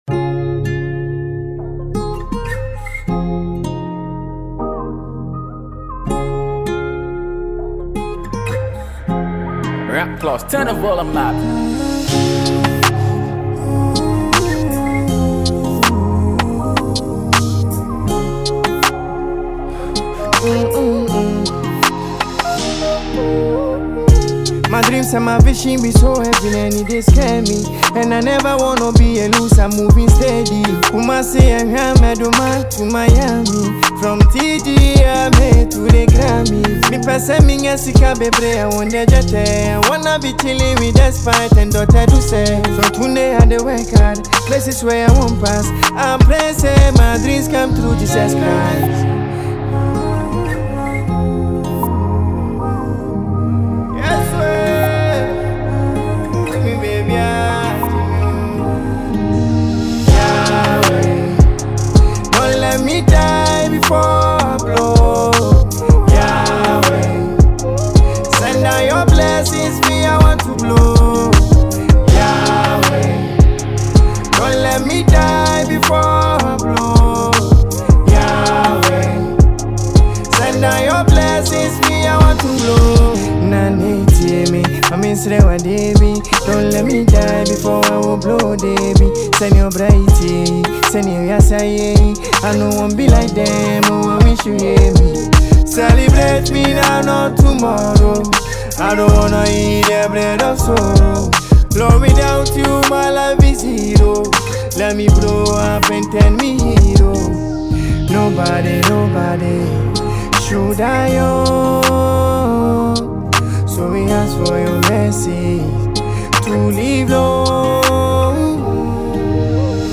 Enjoy this amazing studio track.